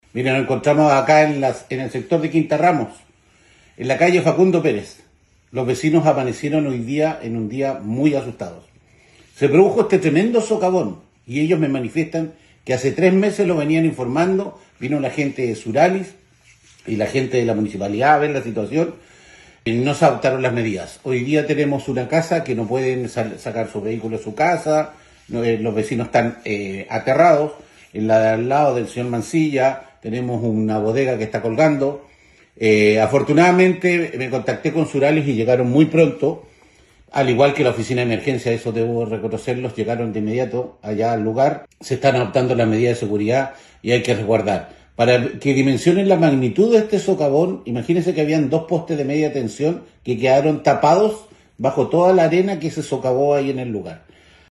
Por su parte, el concejal de Castro Jorge Luis Bórquez también alertó sobre los peligros a los que se ven expuestos por esta emergencia los vecinos del sector.
08-CONCEJAL-SOCAVON-CASTRO.mp3